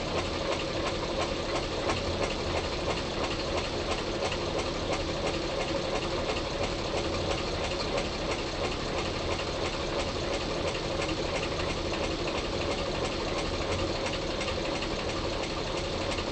I added Hexagonal Boron Nitride to a 1996 Golf MK 3 1.4 Petrol engine, and recorded the operation.
It took 2 min 20 sec to circulate, and the engine noise dropped.
Our ears pick up the fact that the engine components are now rotating with less friction … there is less knocking.
Here is the moment when the HBN completes it’s circulation - at half speed and mixed to mono:
I didn’t have a high quality microphone … I used an LG4 mounted on a tripod.
However, even this is not required, because the transition took approximately 1.5 seconds, and at the outset, the noise dropped significantly, and continued to drop.